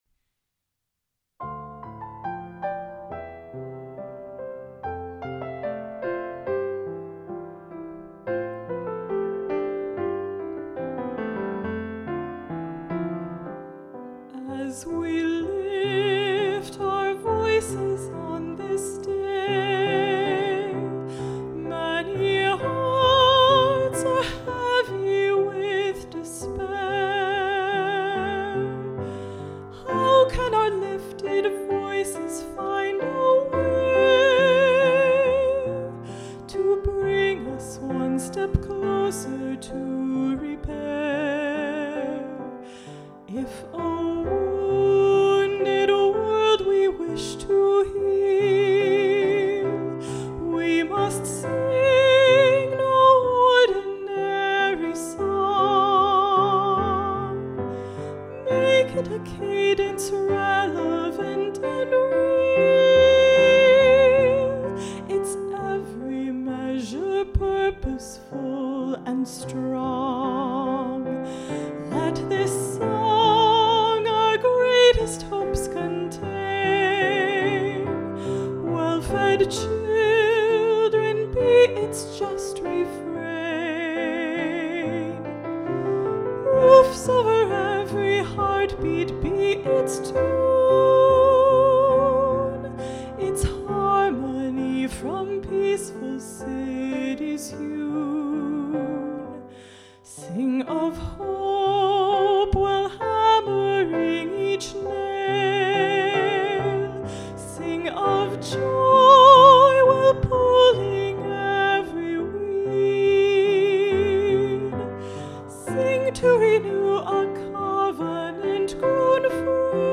Congregation / Medium voice, piano